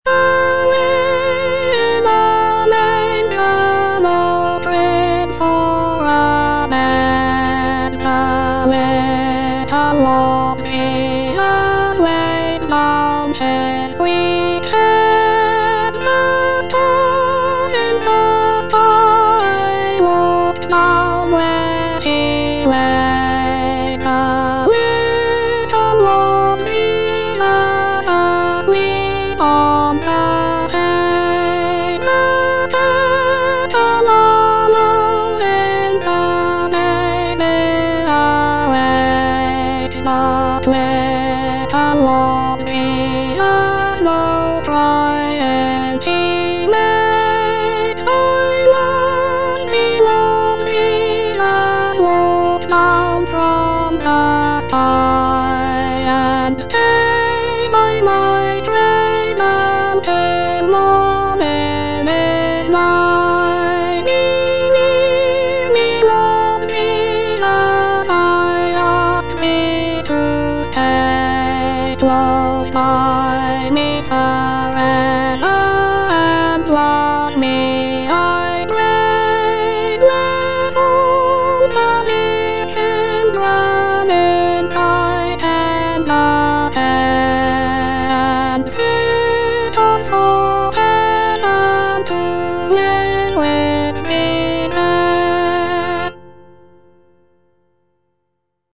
J.R. MURRAY - AWAY IN A MANGER Soprano (Voice with metronome) Ads stop: auto-stop
The gentle and soothing melody of "Away in a Manger" has made it a beloved song for both children and adults during the holiday season.